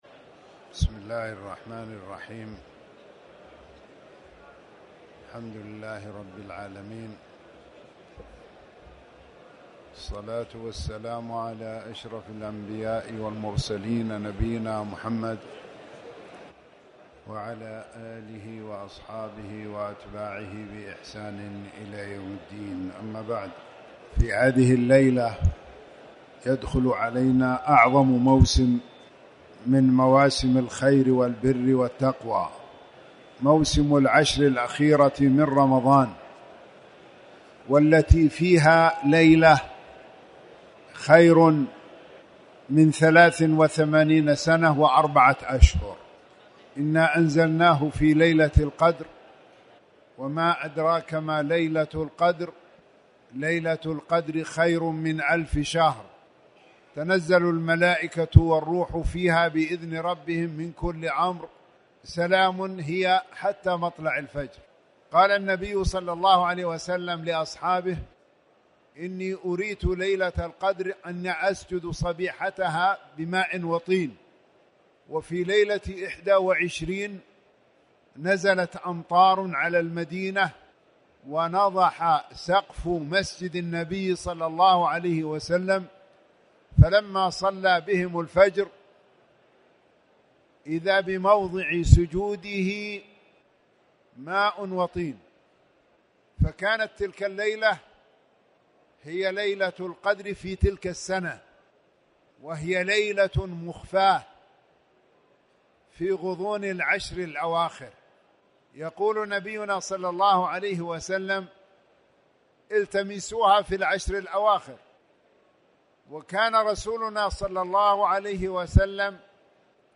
تاريخ النشر ٢٠ رمضان ١٤٣٩ هـ المكان: المسجد الحرام الشيخ